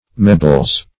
mebles - definition of mebles - synonyms, pronunciation, spelling from Free Dictionary Search Result for " mebles" : The Collaborative International Dictionary of English v.0.48: Mebles \Me"bles\, n. pl.